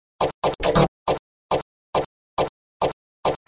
民族大鼓巡游
Tag: 107 bpm Dance Loops Tabla Loops 3.02 MB wav Key : Unknown Cubase